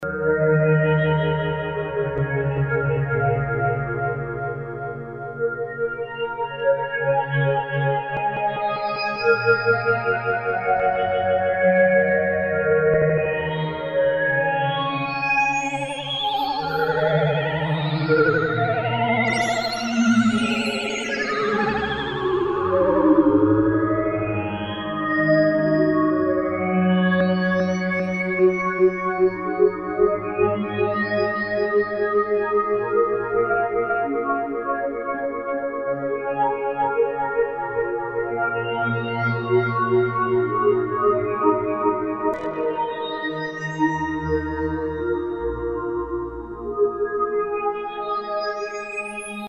German rackmount hybrid analog-digital effect unit from early eighties generates reverb and delay.
Hear modulation